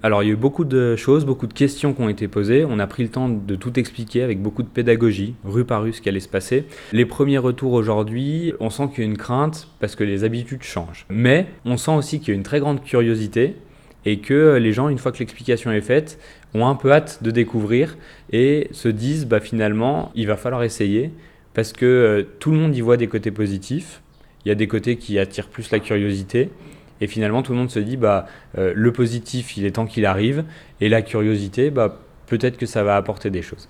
La mairie a organisé le mardi 4 juin 2024 une grande réunion publique pour présenter les détails du projet à la population. Une petite centaine de personnes étaient présentes et voilà ce que Pierrick Ducimetière en retient.